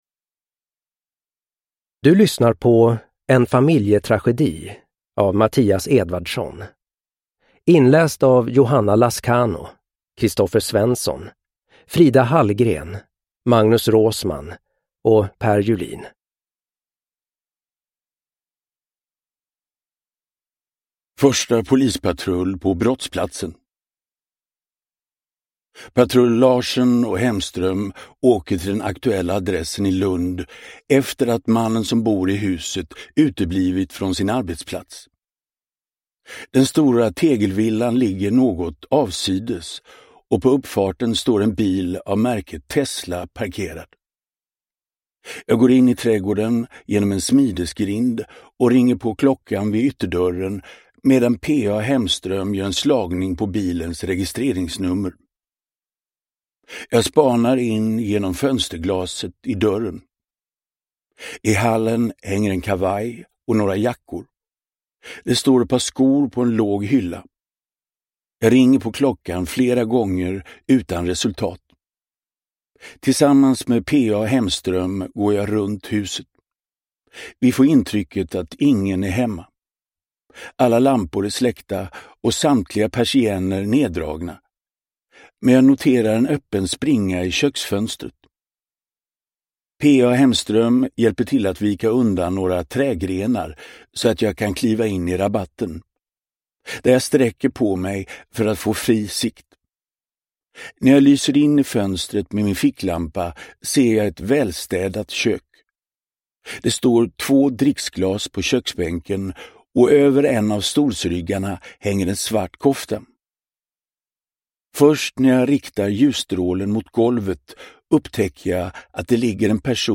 Uppläsare:
Ljudbok